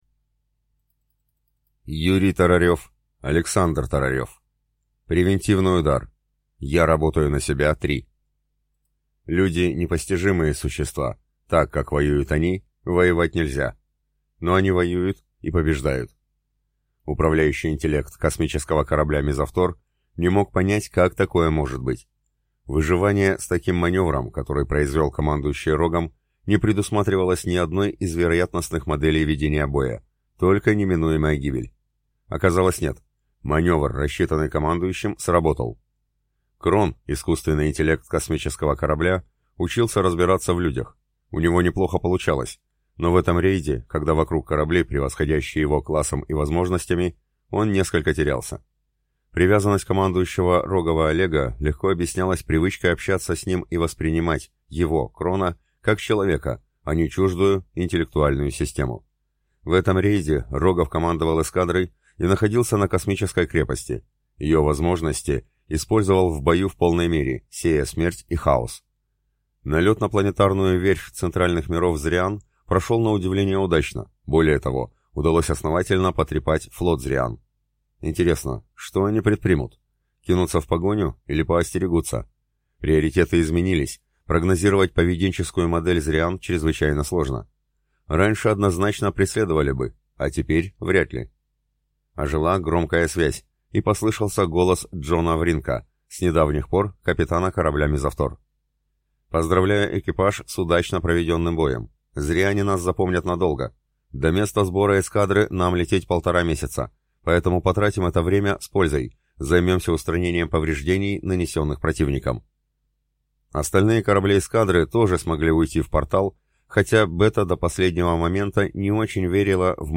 Аудиокнига Превентивный удар | Библиотека аудиокниг